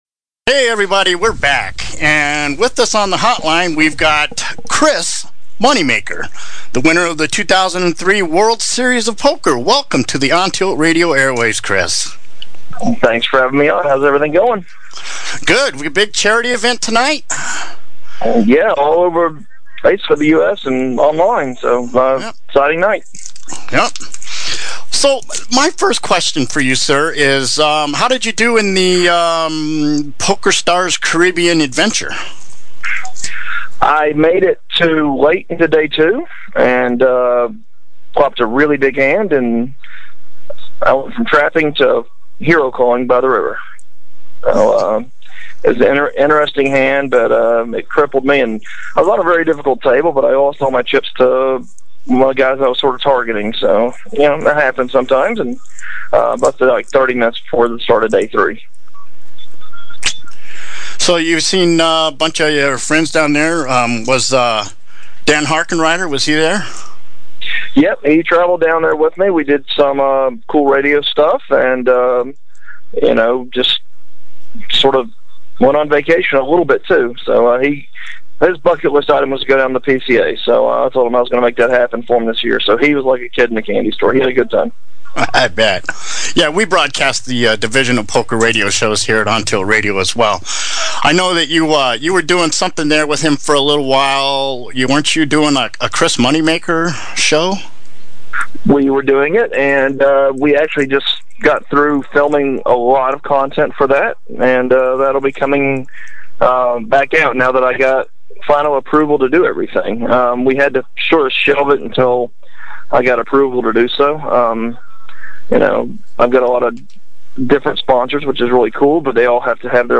Interview with Chis Moneymaker - OnTilt Radio